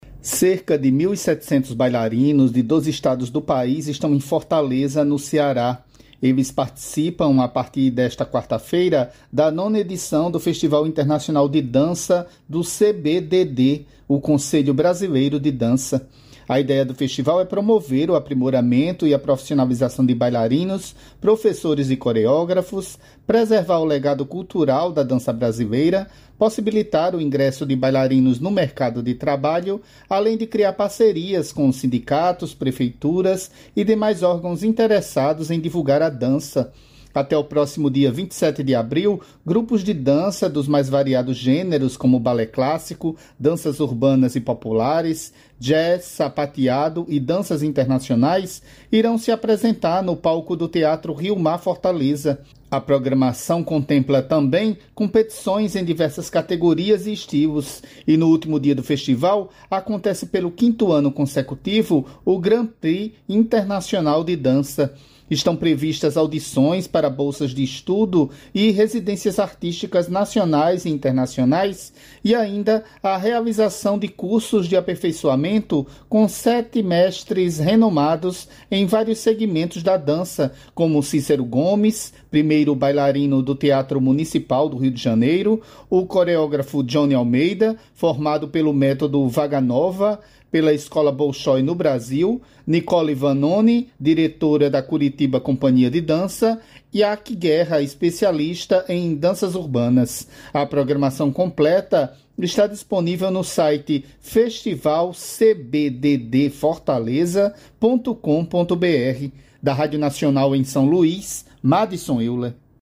Repórter da Rádio Nacional Festival Internacional de Dança Conselho Brasileiro de Dança balé Danças Urbanas jazz quarta-feira